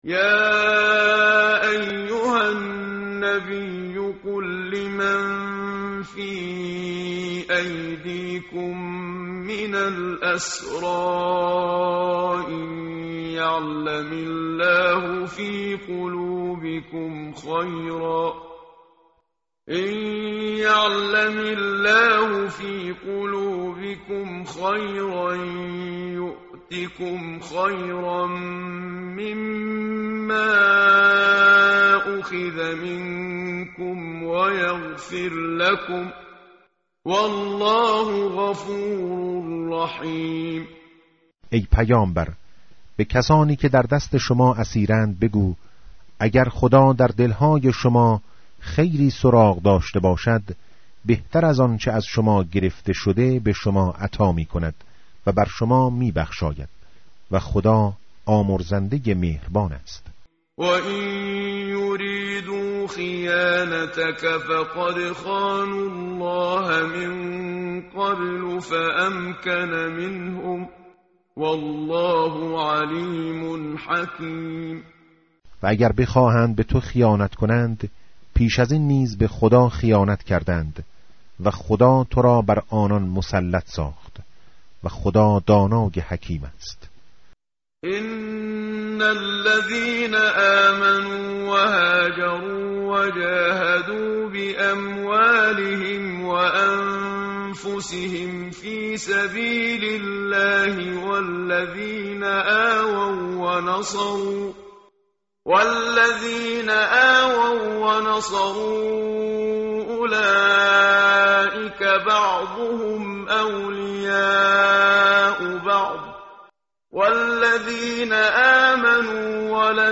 متن قرآن همراه باتلاوت قرآن و ترجمه
tartil_menshavi va tarjome_Page_186.mp3